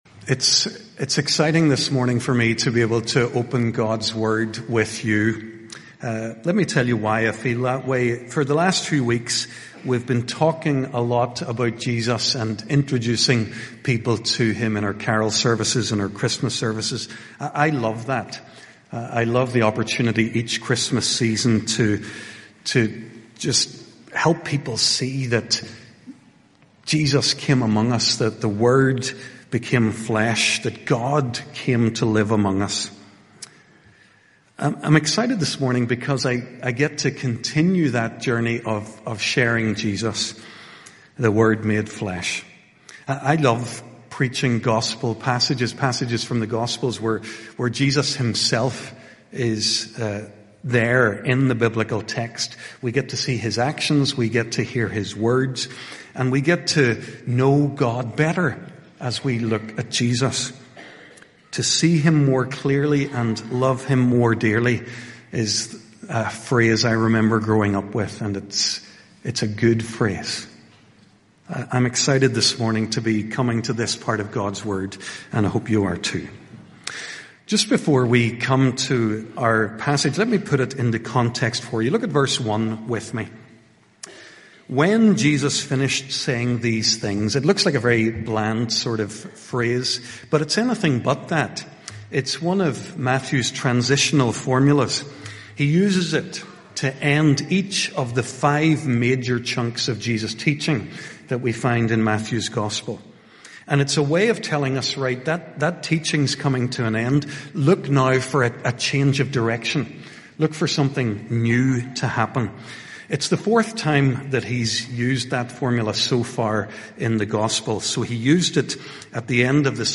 HRPC-Sunday-Morning-Service-Sermon-4th-January-2026.mp3